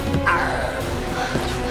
And for me, at least, this seems to possibly involve some pharyngeal frication, and possibly also some additional voice quality modifications.
A really effective arrr will also be quite loud: push the air strongly through those vocal folds, dammit.
rush_arr.wav